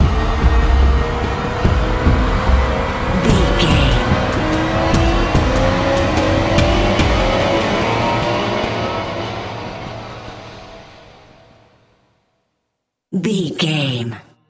Thriller
Aeolian/Minor
synthesiser
drum machine
ominous
dark
suspense
haunting
tense
creepy